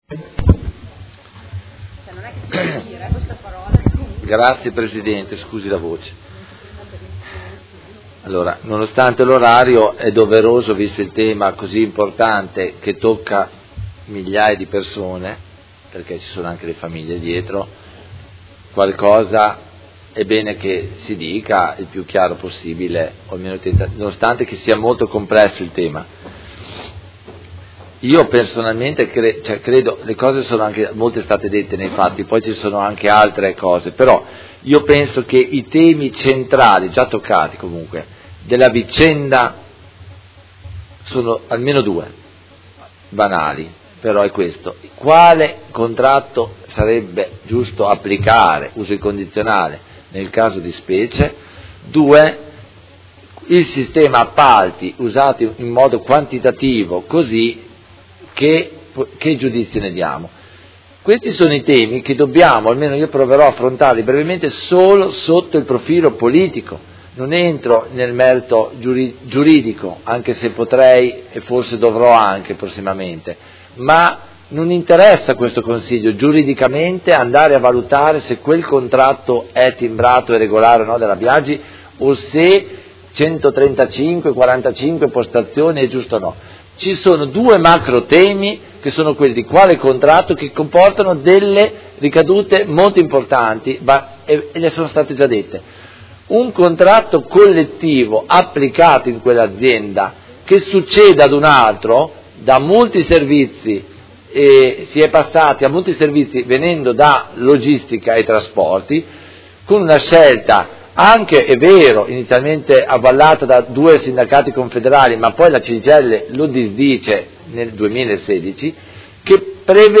Seduta del 24/01/2019. Dibattito su interrogazione, mozioni ed emendamento riguardanti la situazione Società Italpizza S.p.A